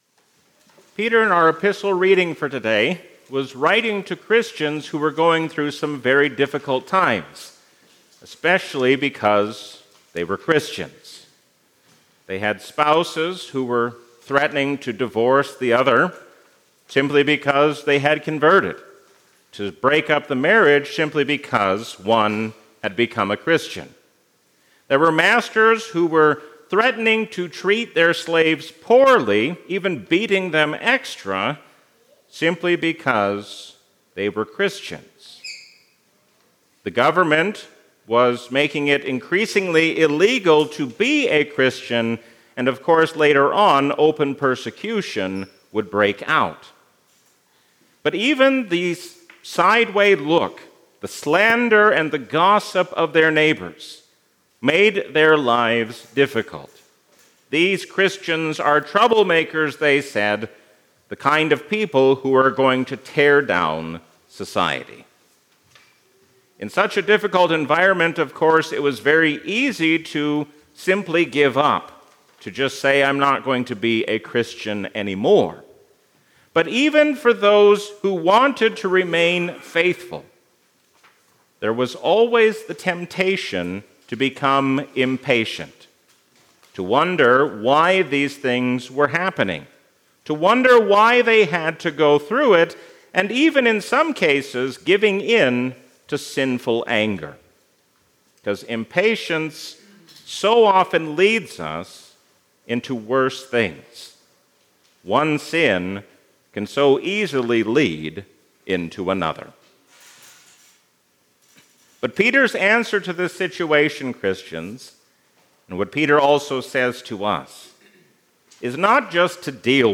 Sermon
A sermon from the season "Trinity 2025." No matter how much the world changes, we can be confident because Jesus does not change.